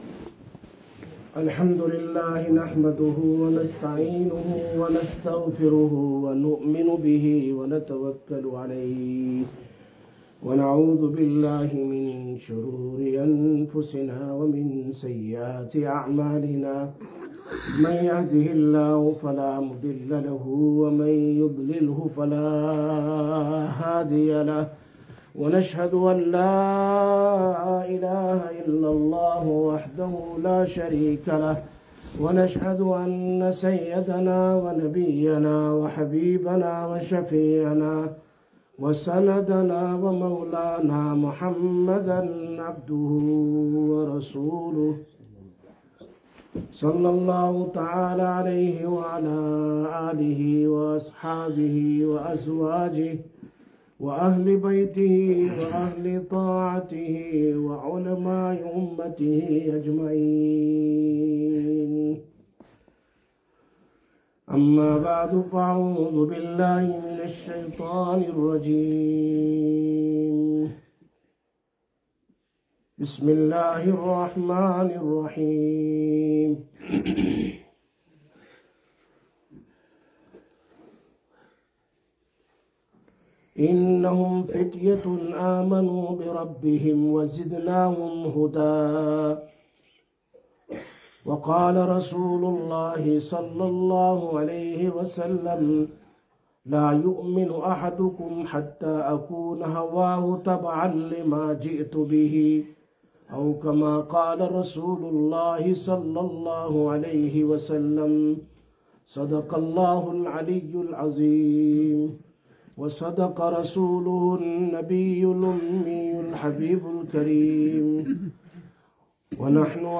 28/04/2023 Jumma Bayan, Masjid Quba